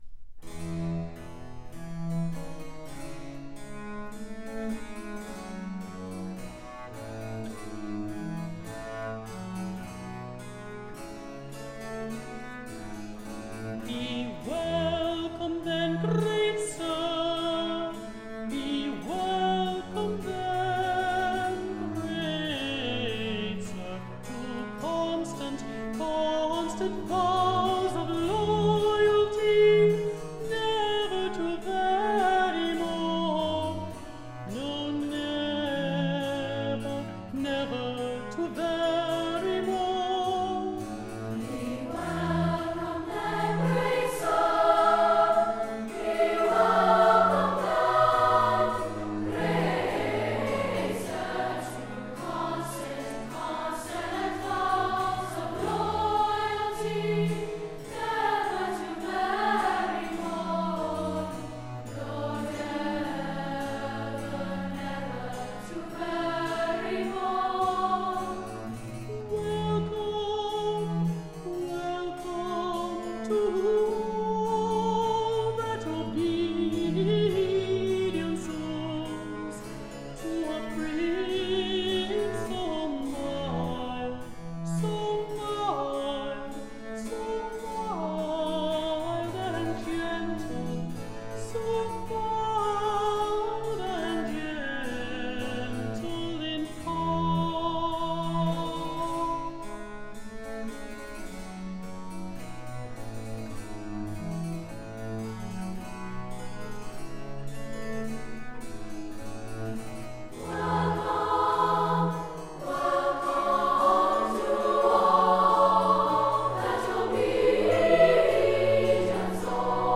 Voicing: S.S.A (keyboard accompaniment)
Countertenor